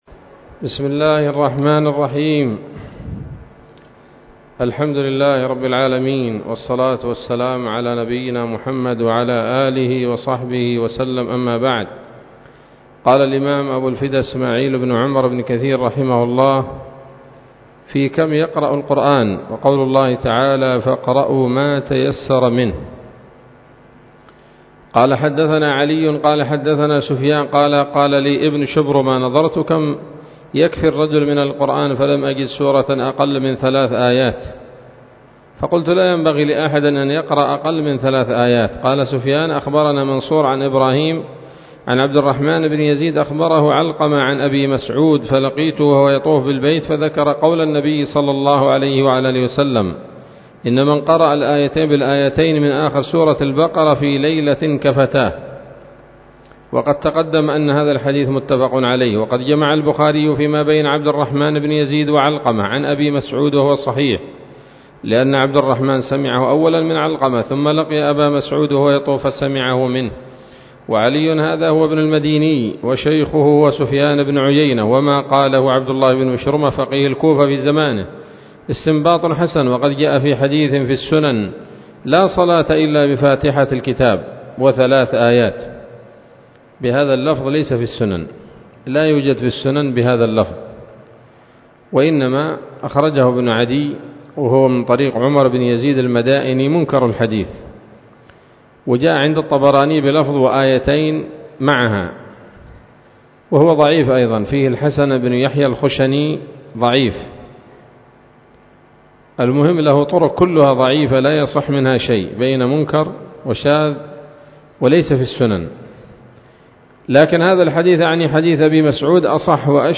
الدرس الثامن والعشرون من المقدمة من تفسير ابن كثير رحمه الله تعالى